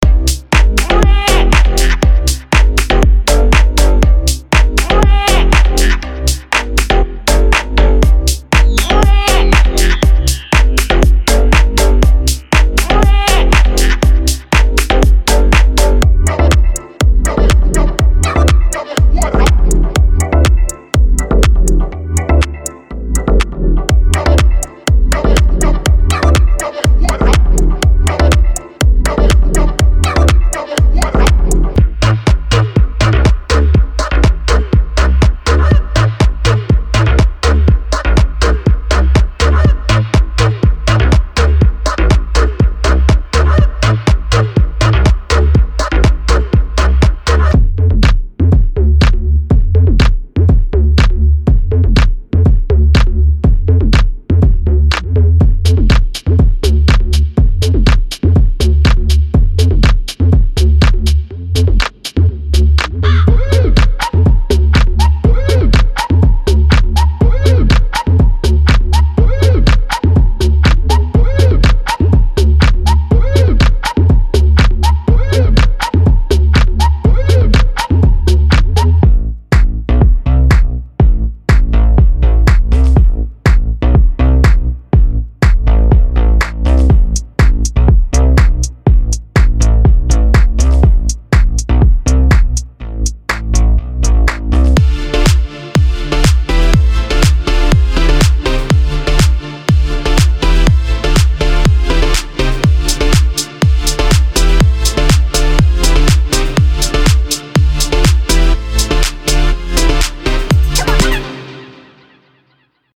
这是一幢深沉，旋律的房子，正向着前瞻性的制作人倾诉，发出清晰，纯净的声音。